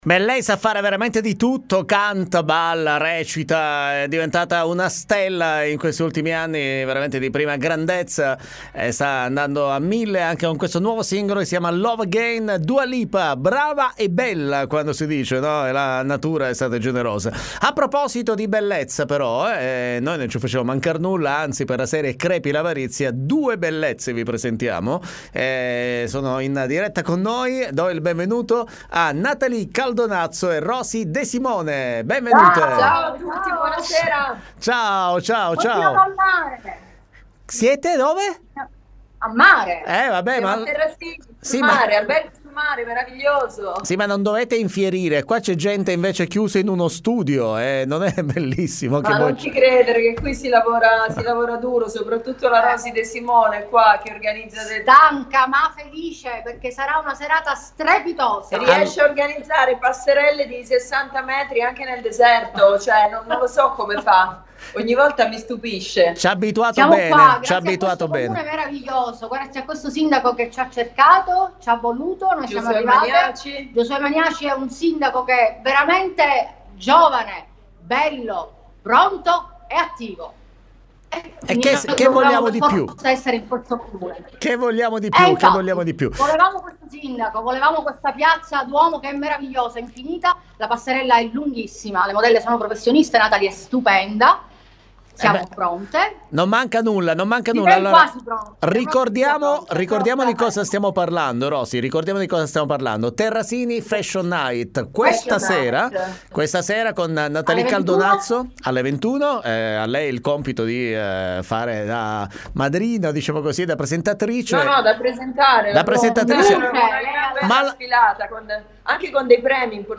D.T. Intervista Natalie Caldonazzo